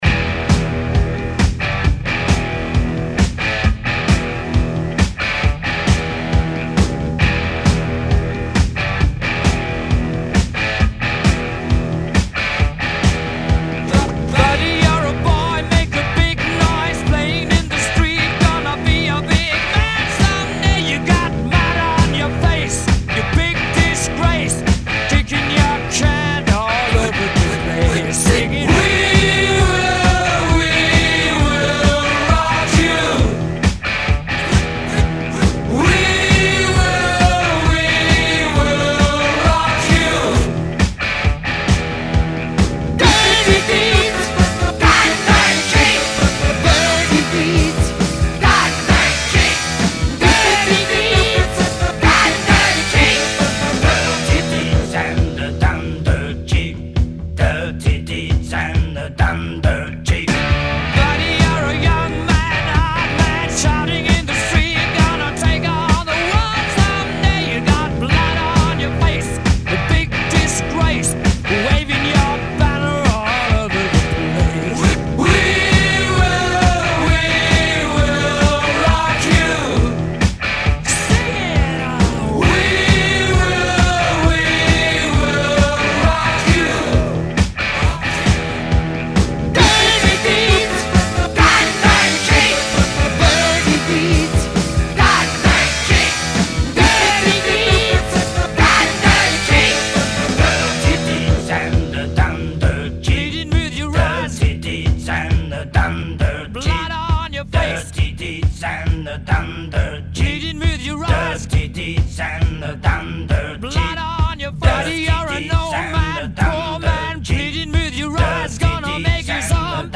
We are still on a mash-up kick.